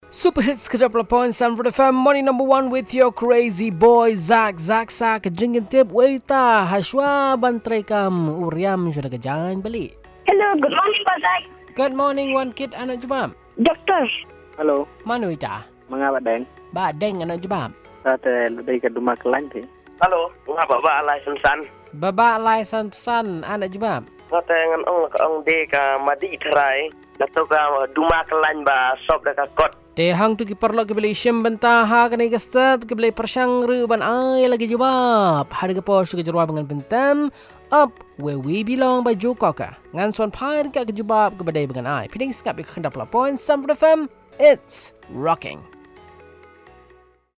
Callers